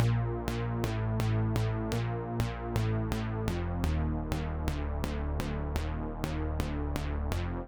IBI Bass Riff Bb-A-F-Eb-D.wav